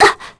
Scarlet-Vox_Damage_01_kr.wav